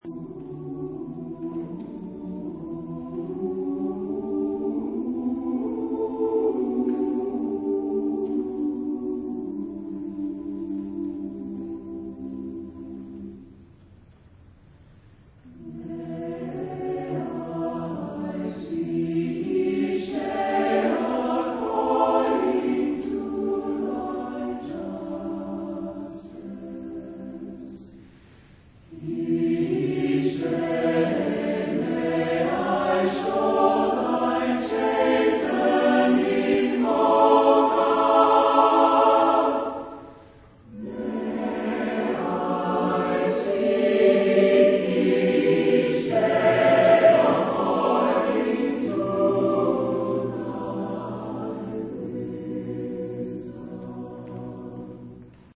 Genre-Style-Form: Partsong ; Sacred ; Invocation
Type of Choir: SATB (div.)  (4 mixed voices )
Tonality: E tonal center
Consultable under : Populaire Anglophone Sacré Acappella